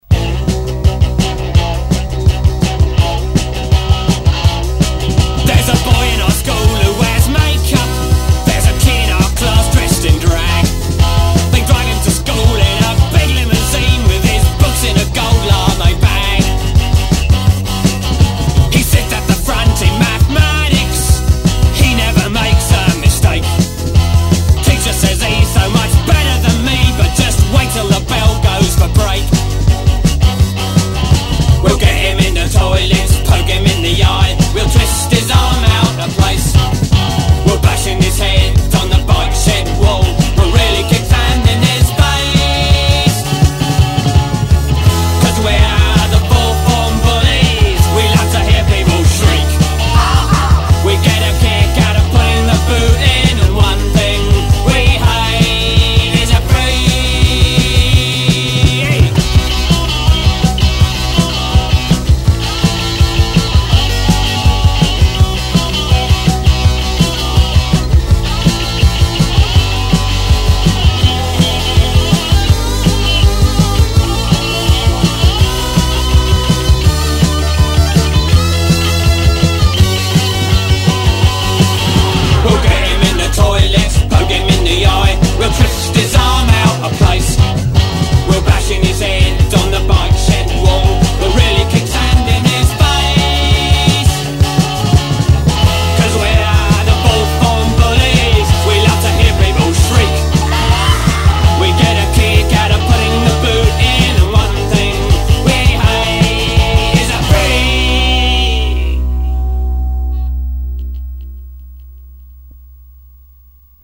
Recording Demo Tape